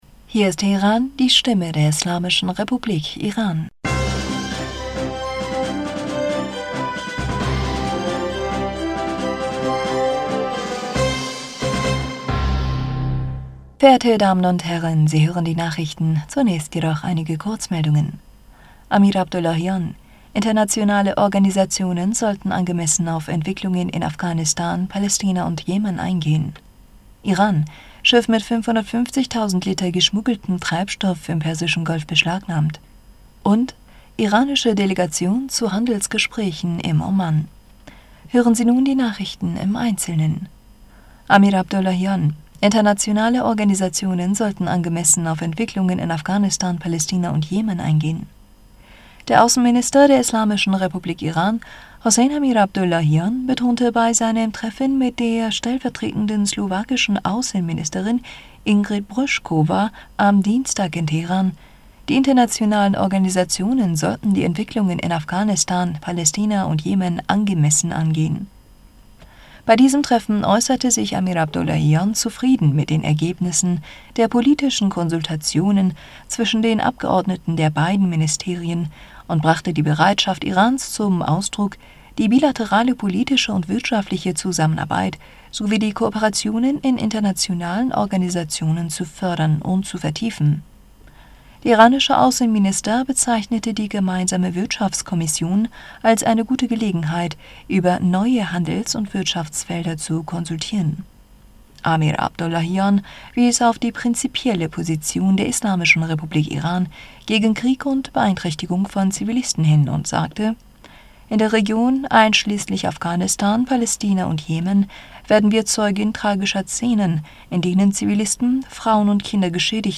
Nachrichten vom 18. Mai 2022
Die Nachrichten von Mittwoch, dem 18. Mai 2022